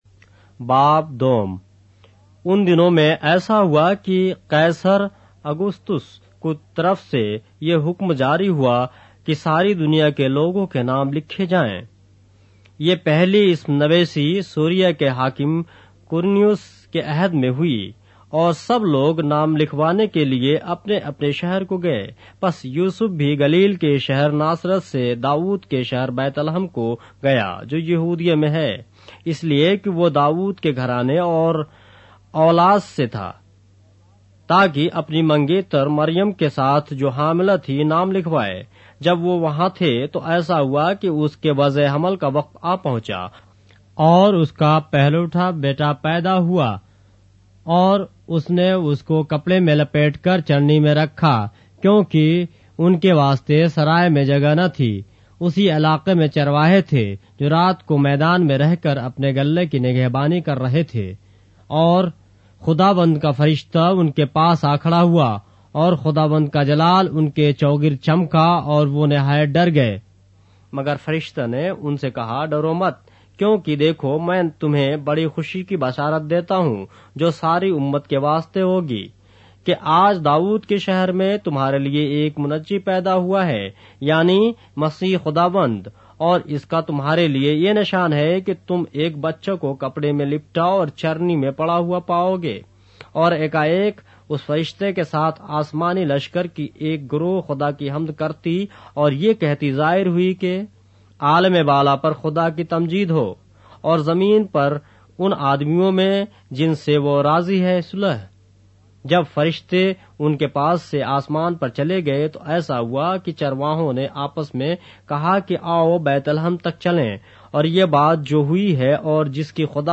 اردو بائبل کے باب - آڈیو روایت کے ساتھ - Luke, chapter 2 of the Holy Bible in Urdu